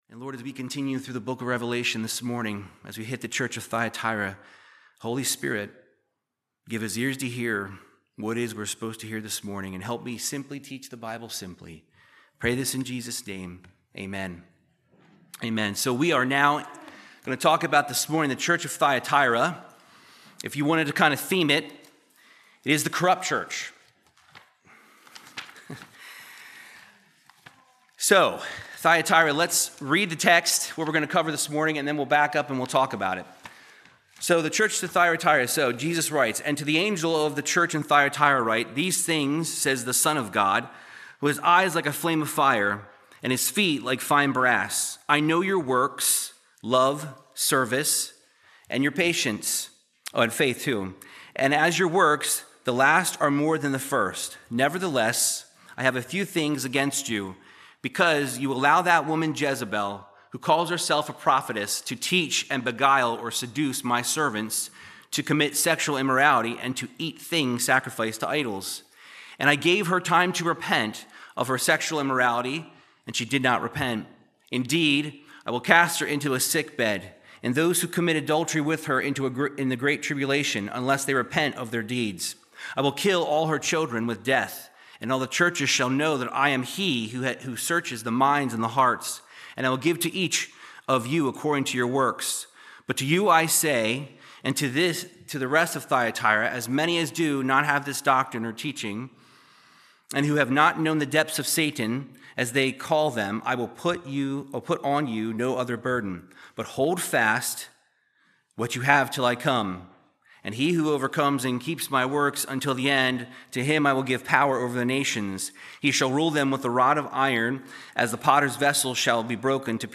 Verse by verse Bible teaching in the book of Revelation chapter 2 verses 18 through 29